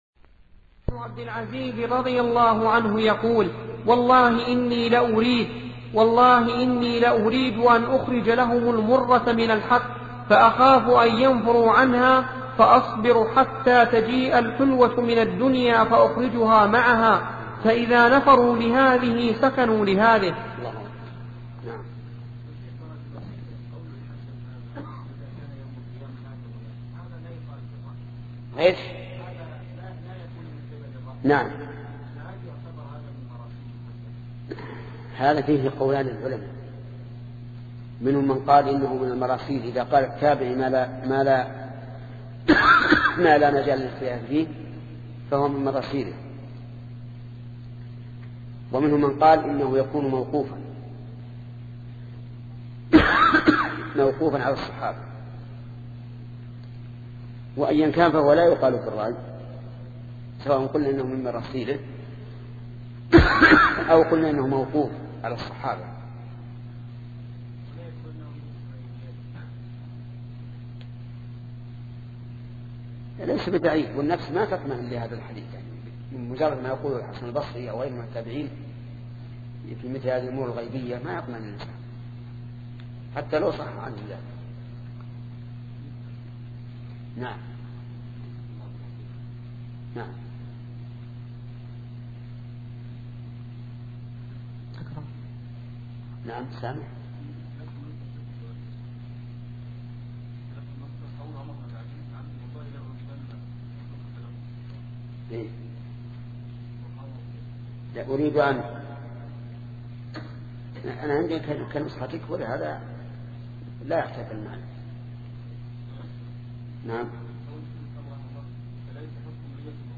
سلسلة مجموعة محاضرات التعليق على السياسة الشرعية لابن تيمية لشيخ محمد بن صالح العثيمين رحمة الله تعالى